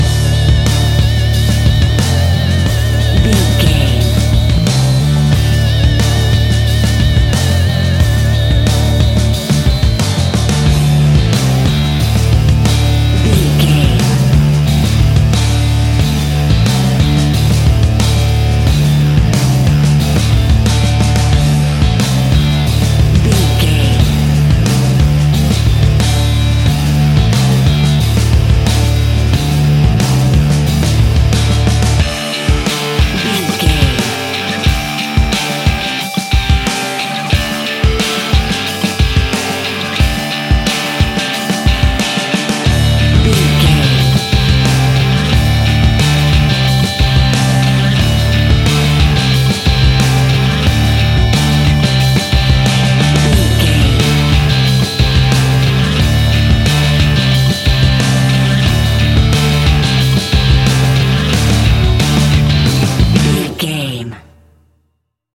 Ionian/Major
D
energetic
driving
aggressive
electric guitar
bass guitar
drums
hard rock
heavy metal
blues rock
distortion
heavy drums
distorted guitars
hammond organ